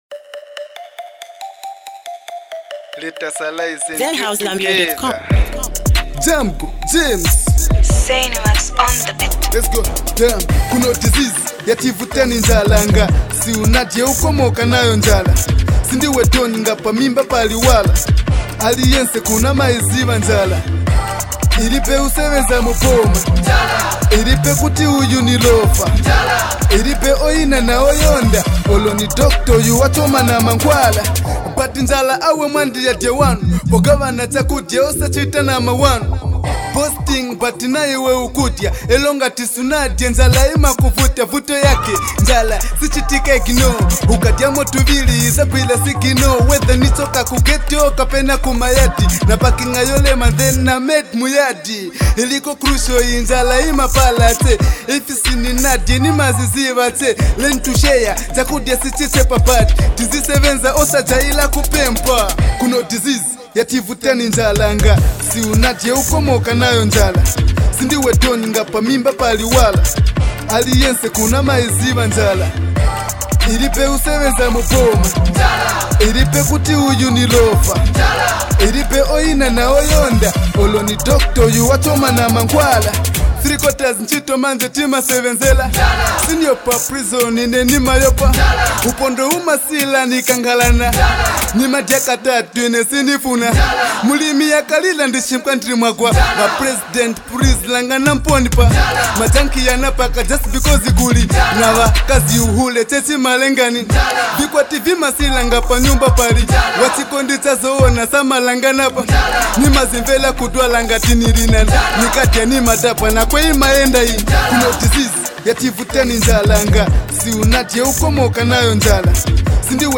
Rapper
With hard-hitting lyrics and raw emotion